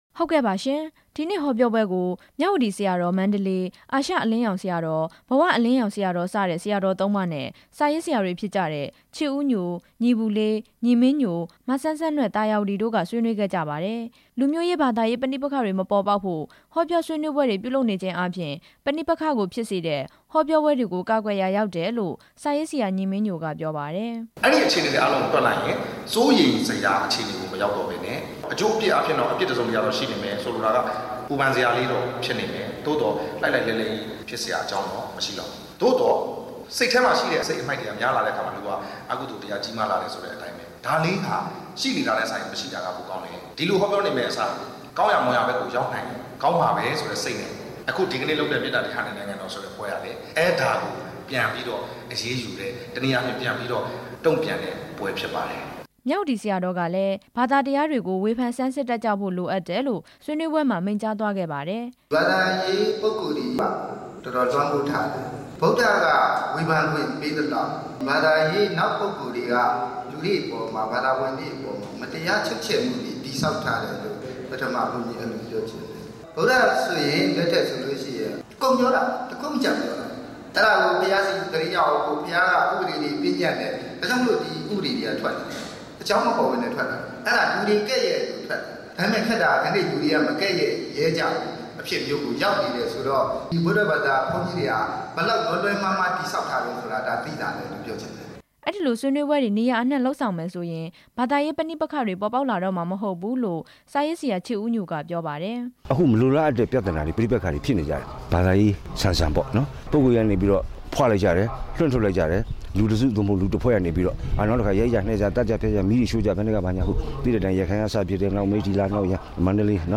ပြင်ဦးလွင်မြို့ အာရှအလင်းရောင် ကျောင်းတိုက်မှာ ကျင်းပတဲ့ အဲဒီဟောပြောပွဲအကြောင်းနဲ့ ပတ်သက်ပြီး စာရေးဆရာချစ်ဦးညိုက  အခုလို ပြောပါတယ်။